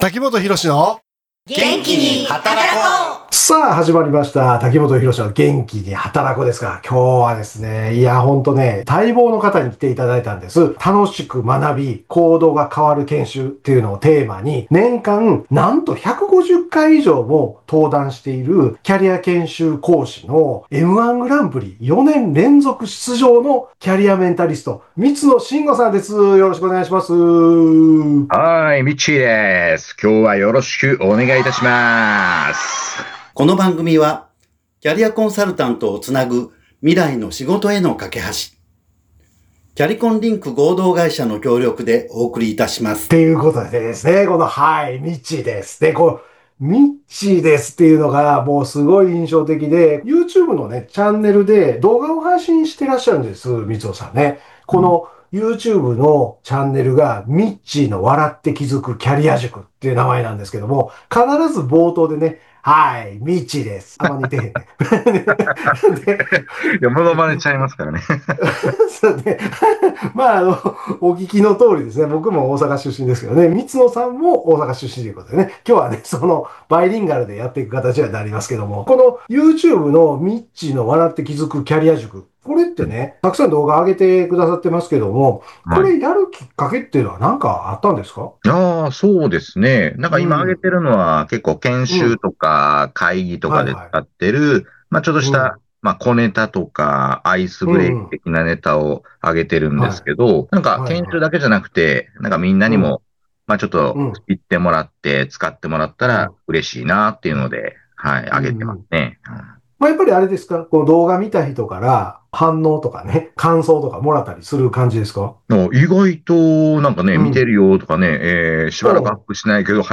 関西らしい軽快なトークの裏側には、人の成長を本気で応援するプロとしての哲学が詰まっています。